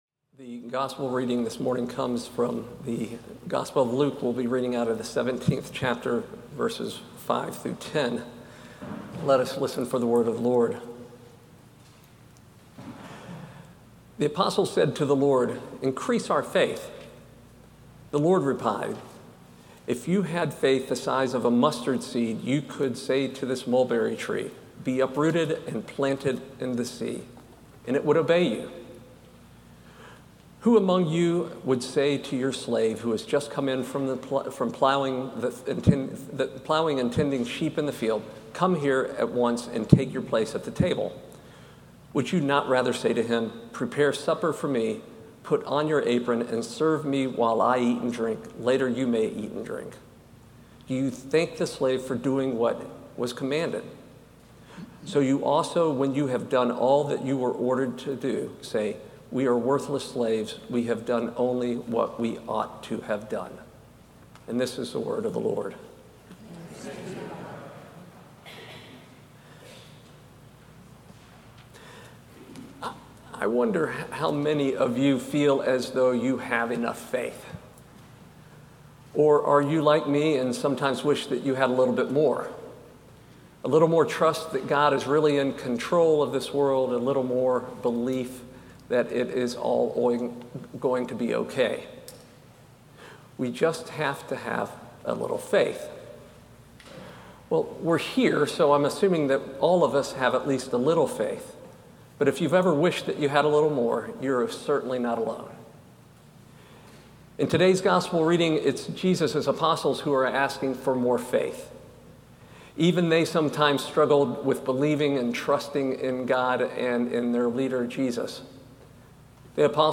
Audio Sermons details